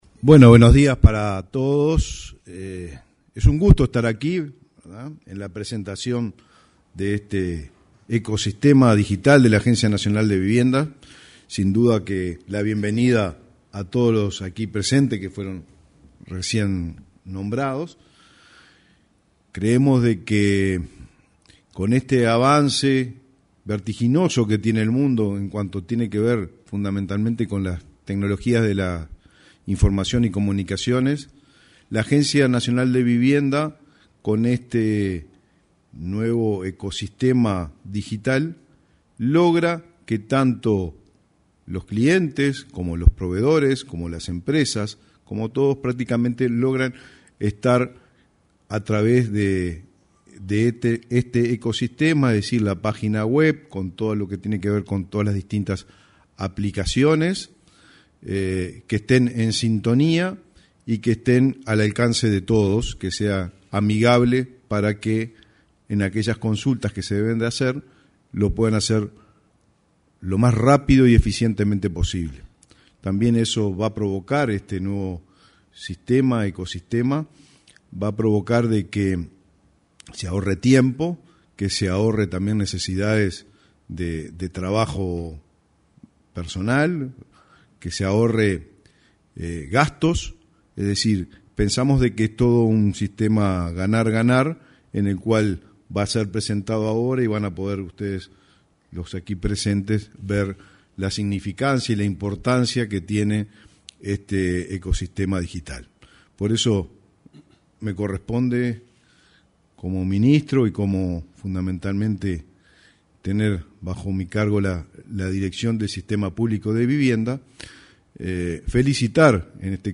Este martes 12, se realizó, en el salón de actos de la Torre Ejecutiva, la presentación del sitio web y la aplicación móvil de la Agencia Nacional de